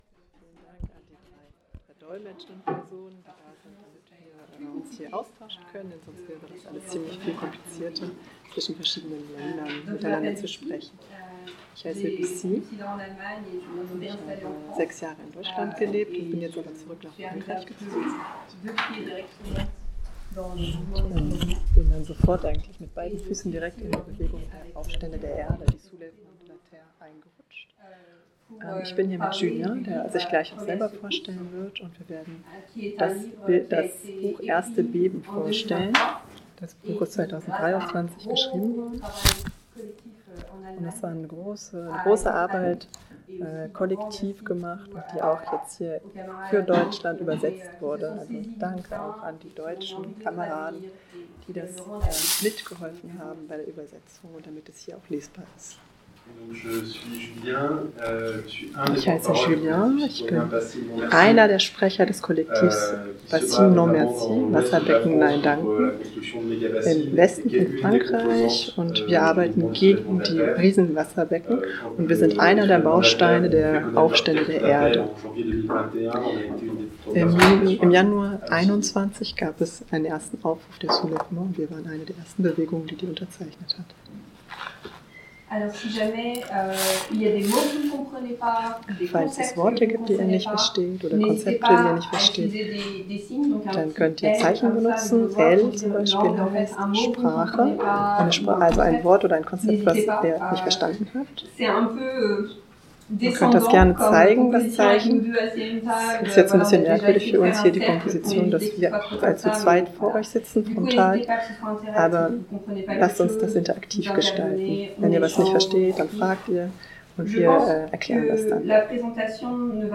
Wasserkonferenz: Buchvorstellung (in dt. Übersetzung) „Erstes Beben“ – Aufstände der Erde – radio nordpol